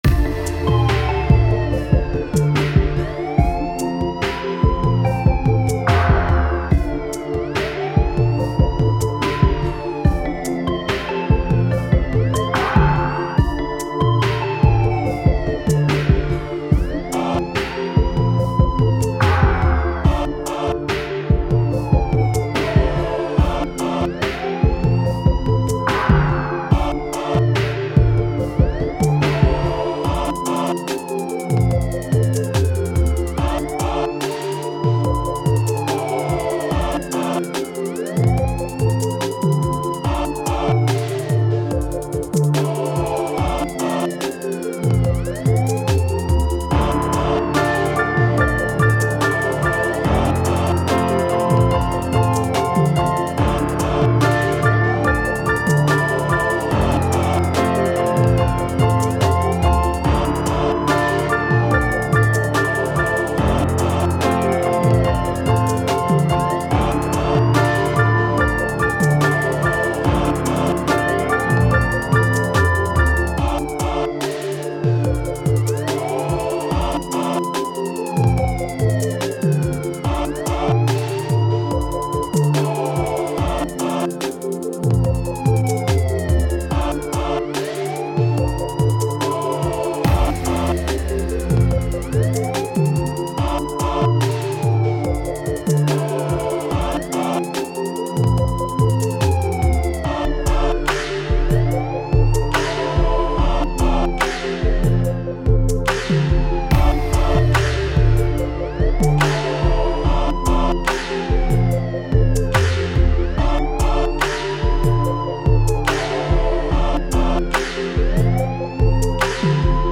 Mi arte sonoro es abiertamente pop.